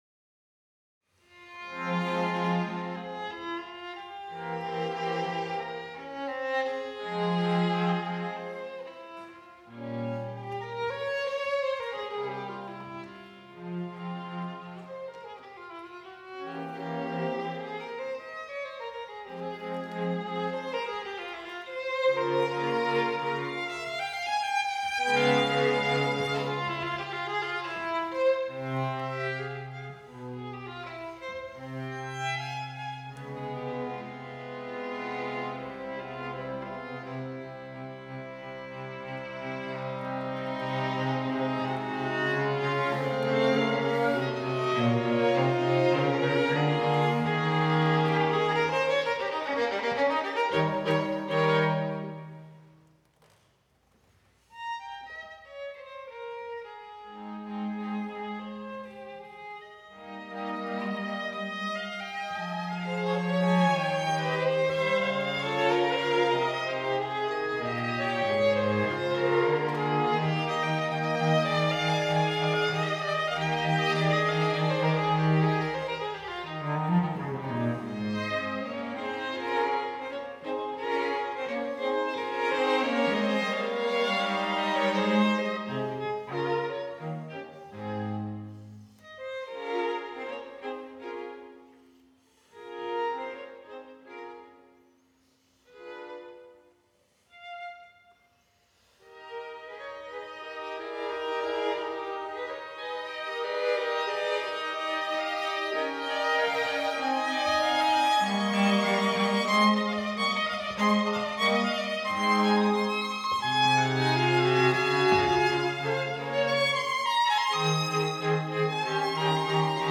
Finale; fuga a due soggetti This is the darker of the two minor key works in Opus 20.
Venue: St. Brendan’s Church
Instrumentation: 2vn, va, vc Instrumentation Category:String Quartet
Haydn_stringquartet_fminor_op205.mp3